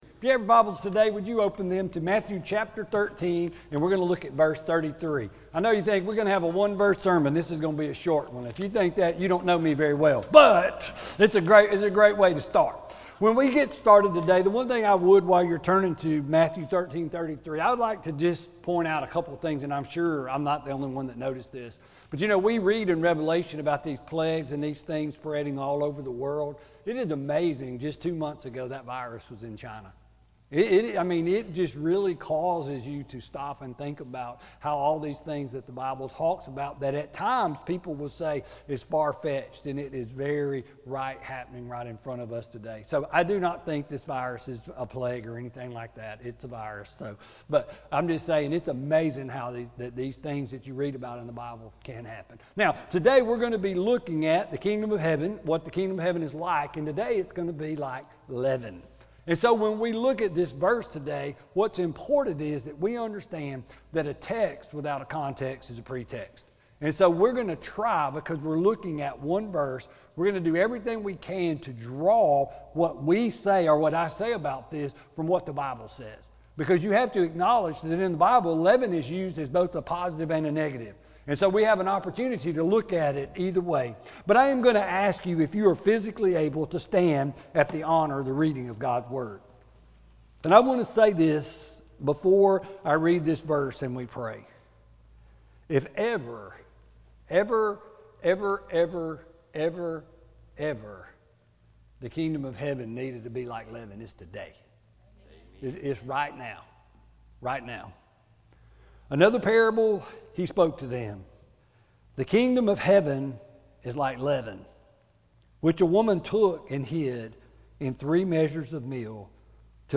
Sermon-3-15-20-CD.mp3